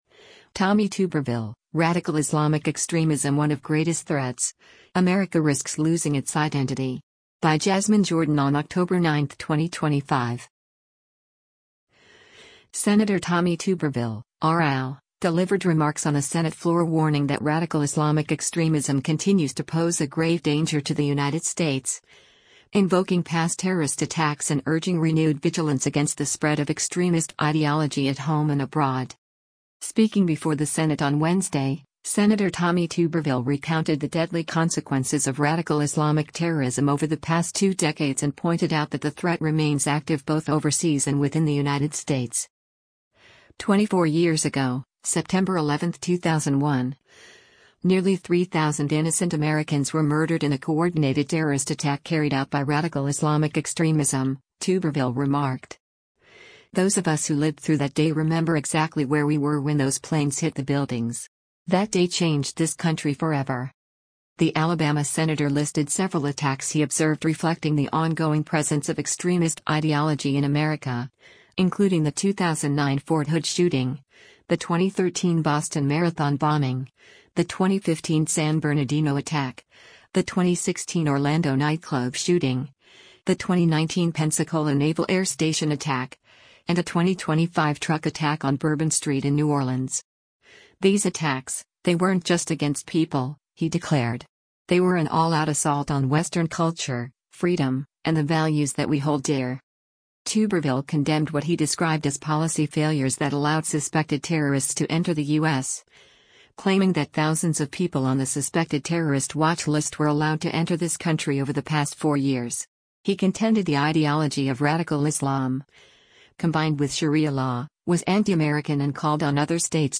Sen. Tommy Tuberville (R-AL) delivered remarks on the Senate floor warning that radical Islamic extremism continues to pose a grave danger to the United States, invoking past terrorist attacks and urging renewed vigilance against the spread of extremist ideology at home and abroad.
On the Senate floor, Tuberville concluded his speech by urging immediate action to prevent the spread of extremist ideology.